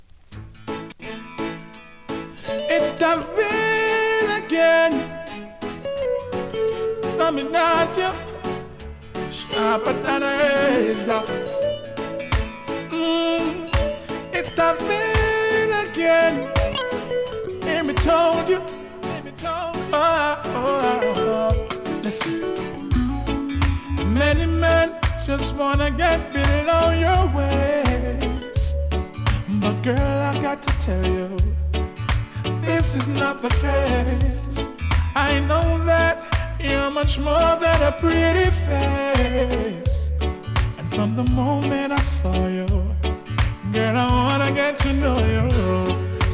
2026 NEW IN!! DANCEHALL!!
スリキズ、ノイズかなり少なめの